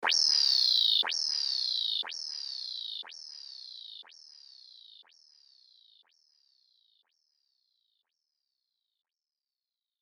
Turtle